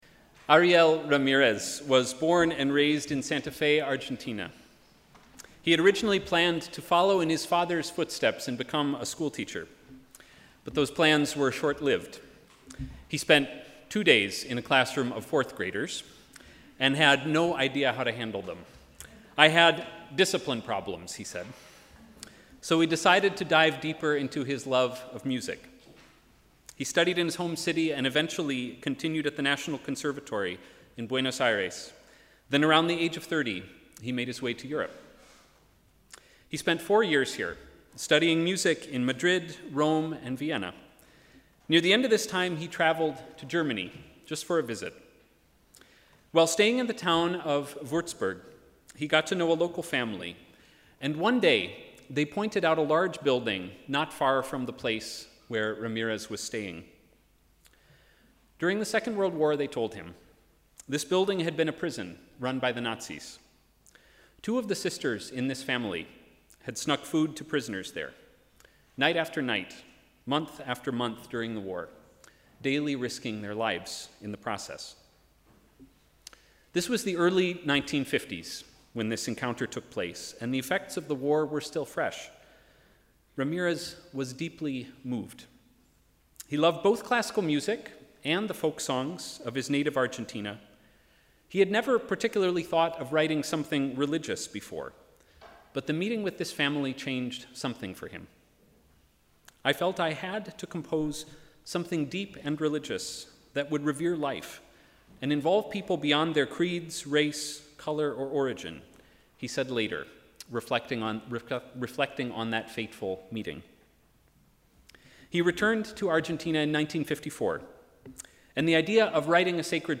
Choir of the congregation, Tenor solo
Guitar
Percussion
Piano
Trumpet